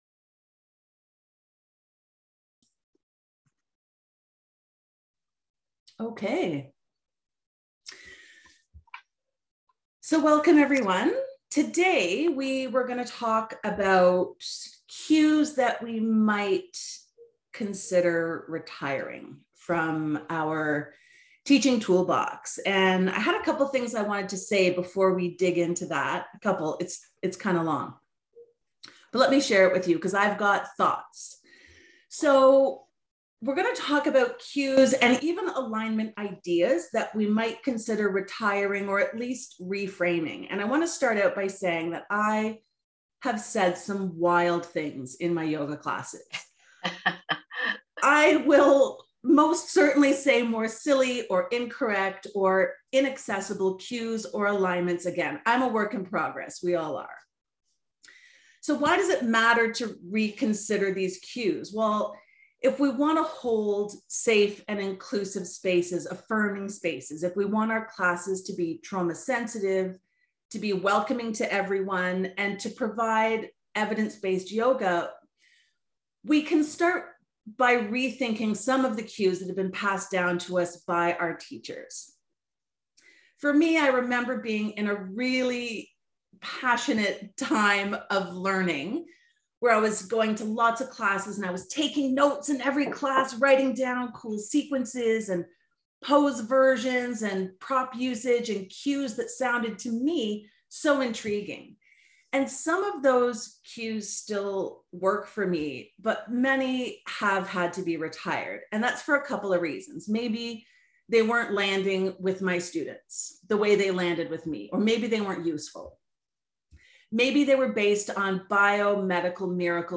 Listen in or read my intro notes from a recent group yoga coaching session for yoga teachers where we explore cues and alignment ideas to consider retiring